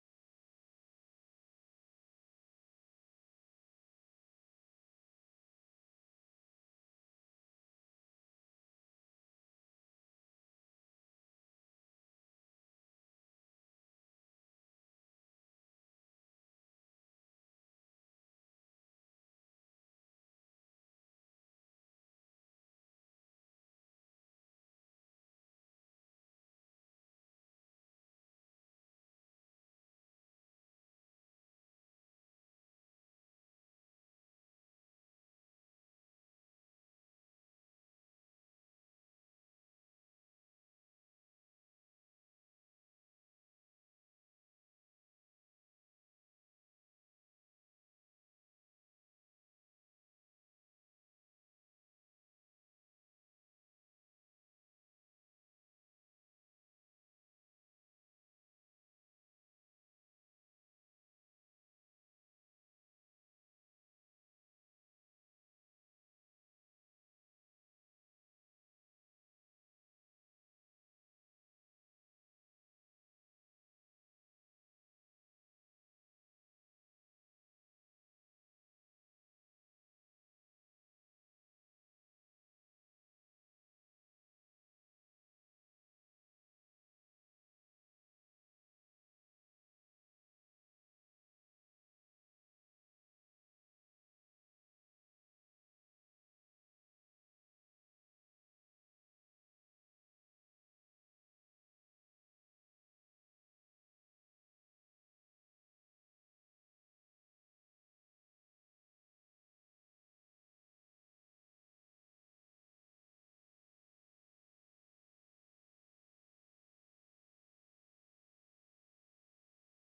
Proclamation Signing Ceremony